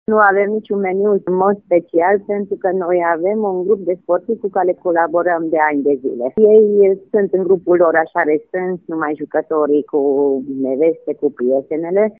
Șefa de sală